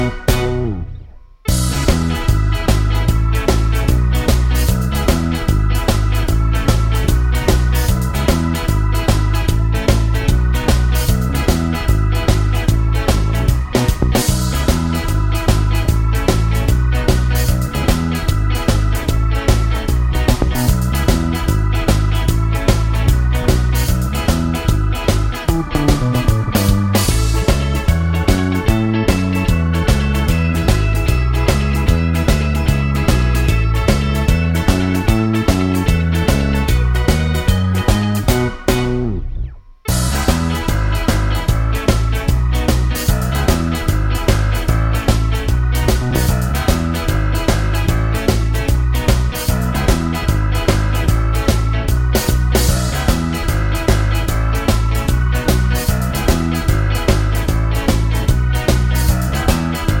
No Backing Vocals Brass Or Harmonica Ska 2:37 Buy £1.50